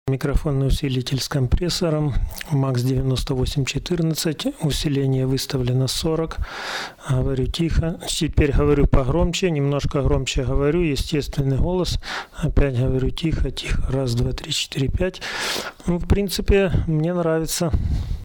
Микрофонный усилитель с компрессором на MAX9814.
И , как по мне , много НЧ в сигнале.
задувание и плевки портят картину.